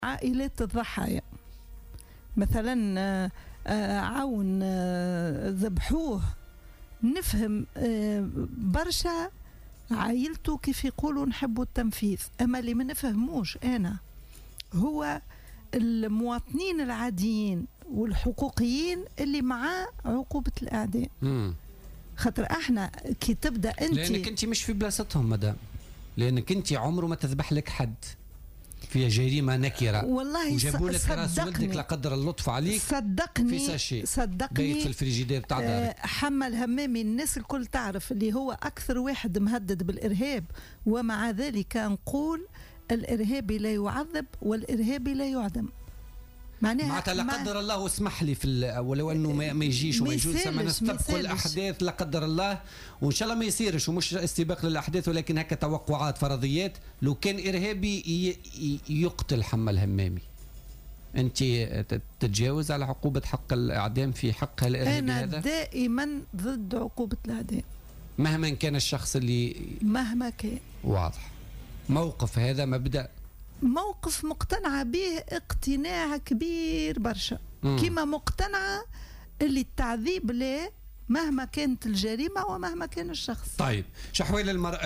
تمسّكت الناشطة الحقوقية راضية نصراوي في "الجوهرة أف أم" اليوم الأربعاء، بموقفها الرافض لحكم الإعدام في تونس حتى وإن كان في حق إرهابيين.